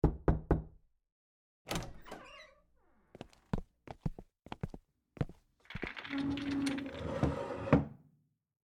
sfx_s04_黑屏转场加洗牌.ogg